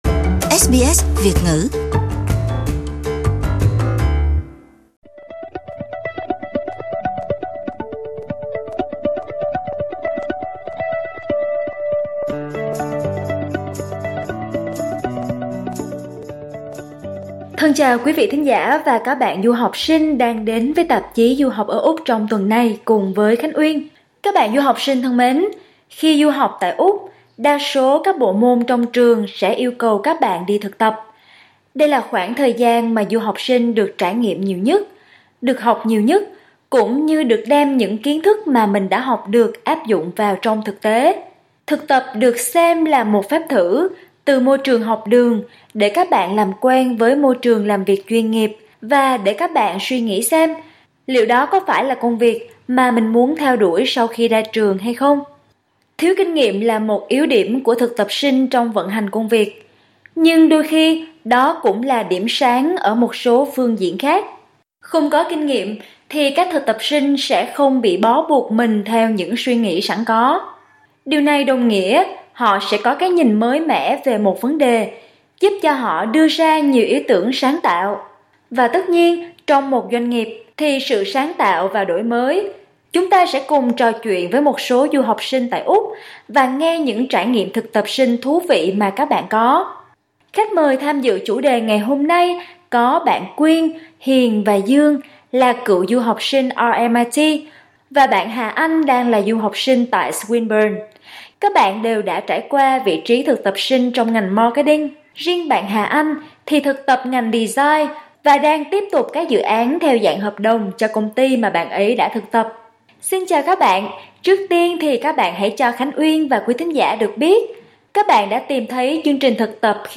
Du học ở Úc (231): Du học sinh kể chuyện thực tập tại Úc